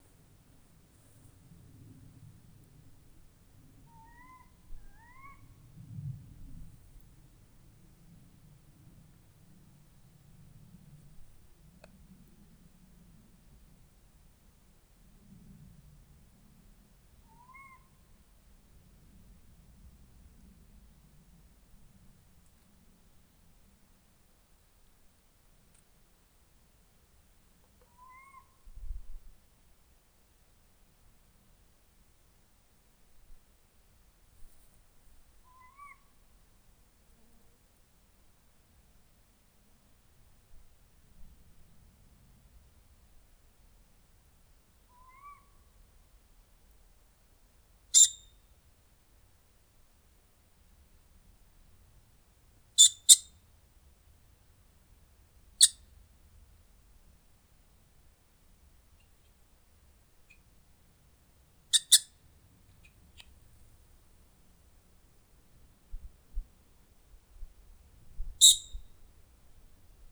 # 2024-57 Boreal Owl Note